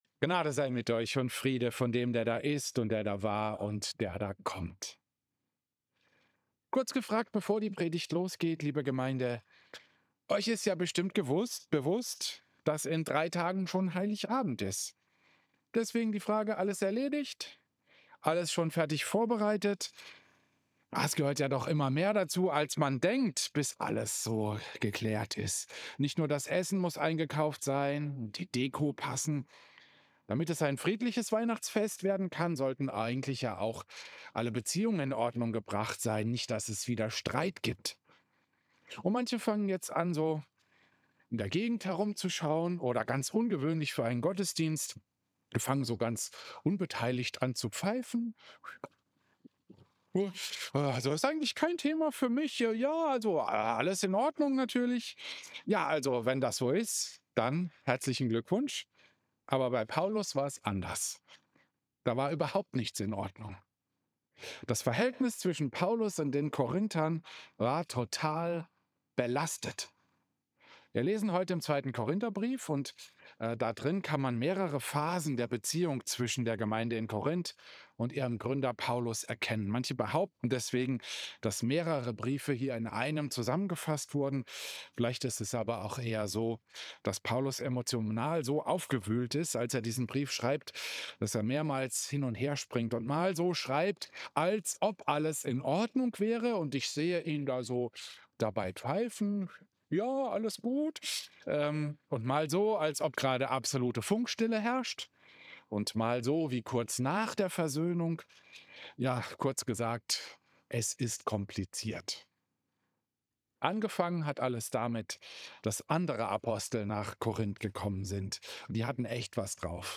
Klosterkirche Volkenroda, 21. Dezember 2025